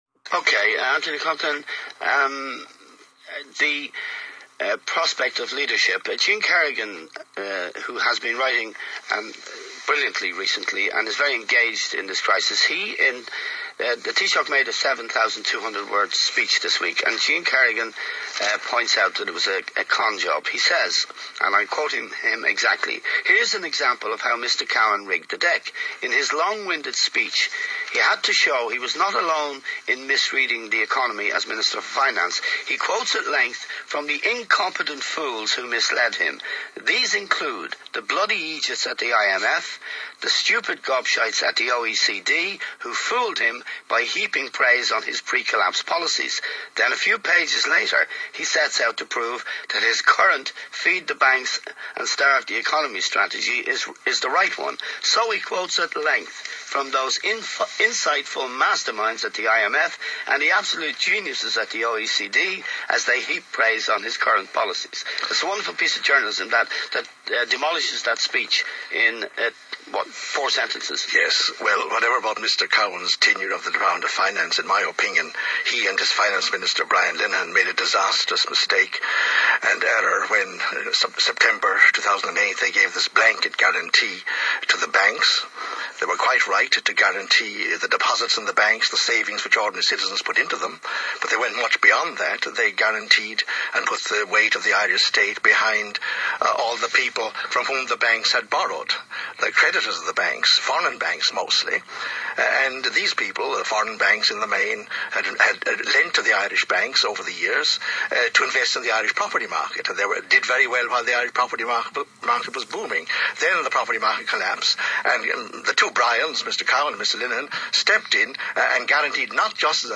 with his panel of guests, to discuss the Irish and European economies, and the trials and tribulations of the Euro